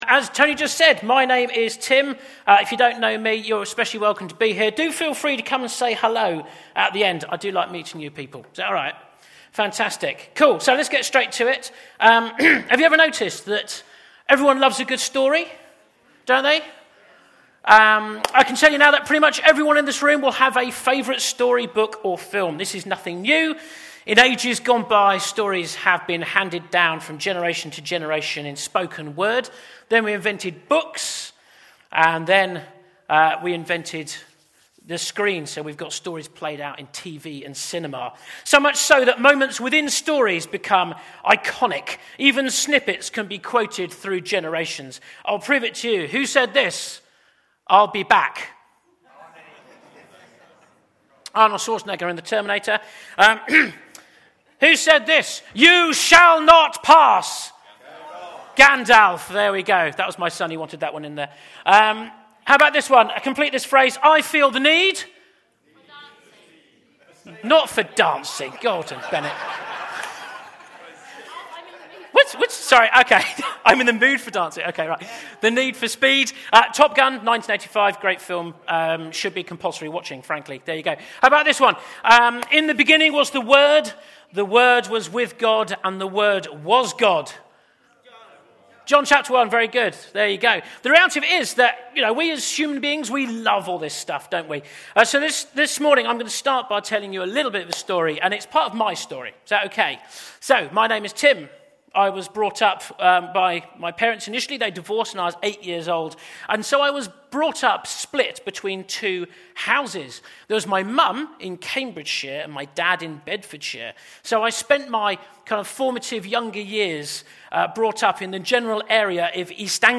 Grace Church Sunday Teaching